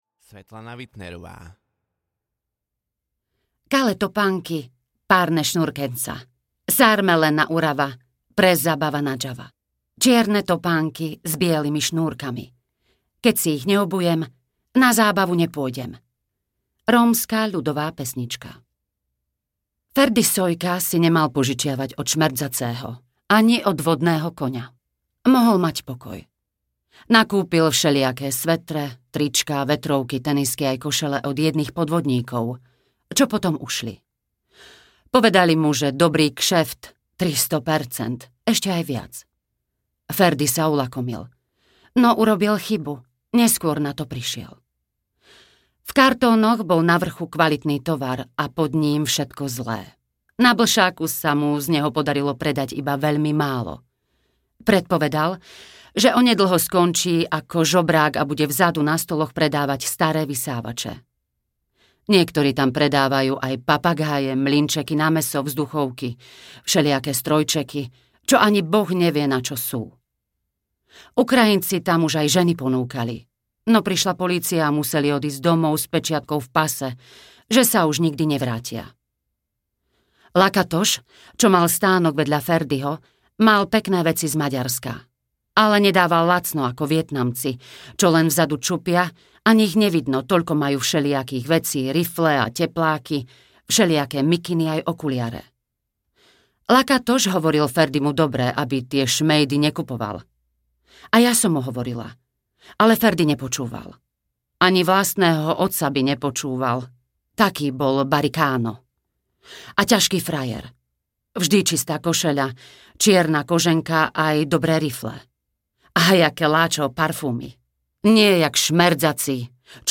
Audio knihaKale topanky
Ukázka z knihy